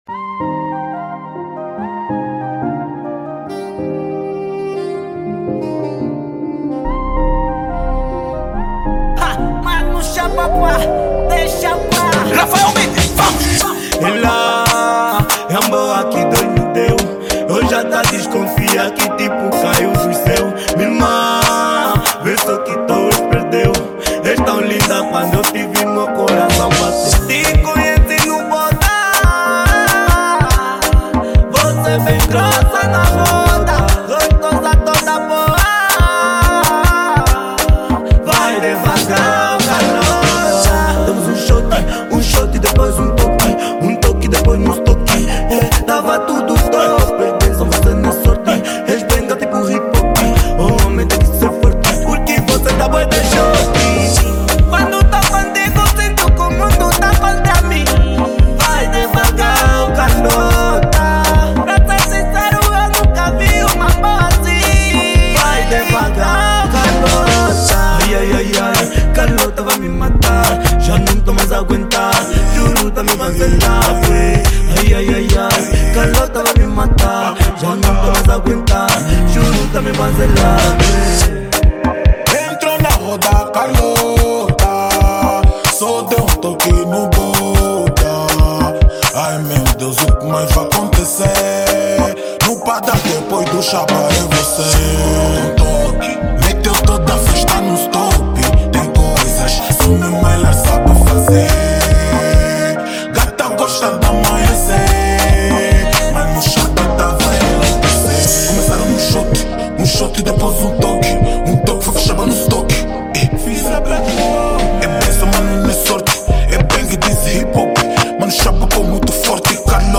Genero: Kuduro